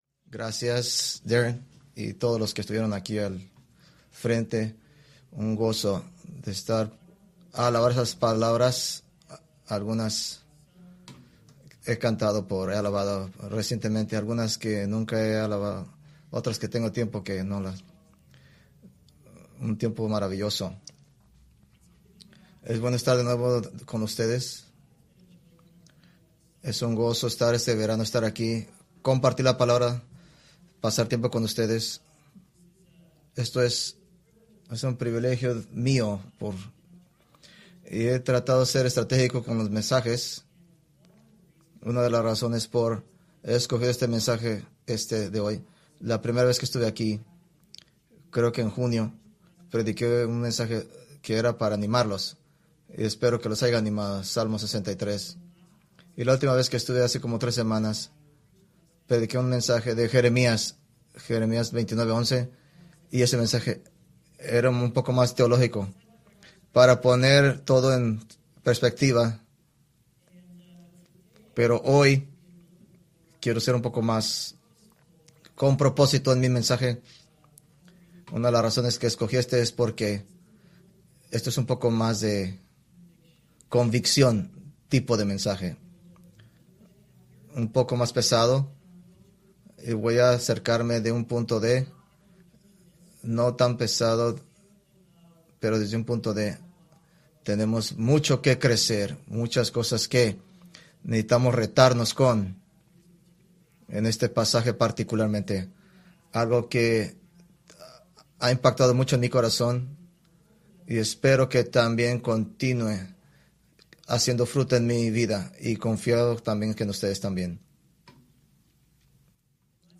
Preached August 11, 2024 from James 1:9-11